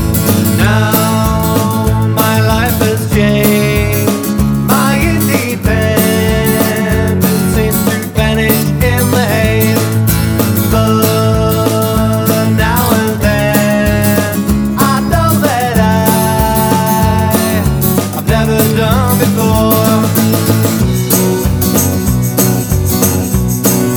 Pop (1960s) 2:16 Buy £1.50